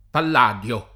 pall#dLo] agg. («di Pallade»); pl. m. -di (raro, alla lat., -dii) — come s. m., con P‑ maiusc. nel sign. proprio («simulacro di Pallade»), con p‑ minusc. nel sign. fig. («suprema difesa e garanzia»): il rapimento del P. da Troia; il parlamento, p. delle libere istituzioni — sim. il pers. m. stor. P., assunto poi come nome umanistico dall’architetto Andrea di Pietro (Andrea Palladio [andr$a pall#dLo], 1508-80), dal letterato Biagio Pallai (Blosio Palladio [